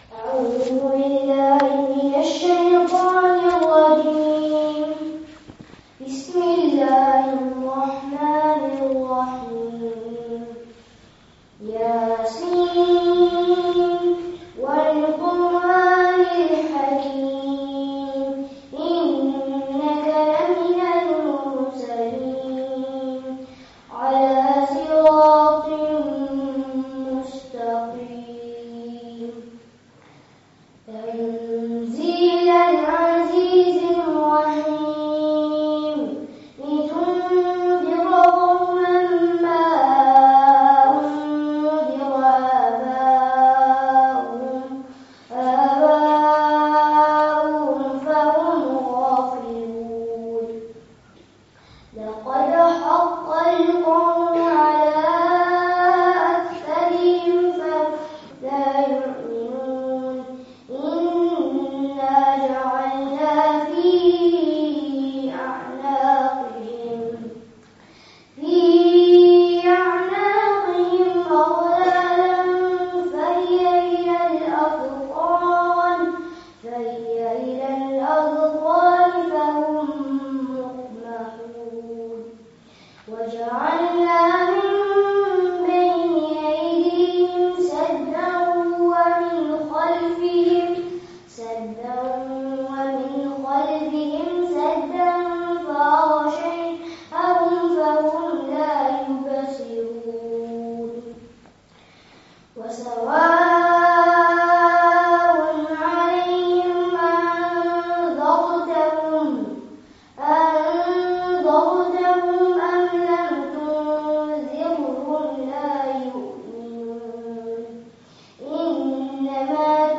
Benefits of Coming to Madrasah [Annual Jalsah] (Madrasah Islamiyah, Leicester 30/08/18)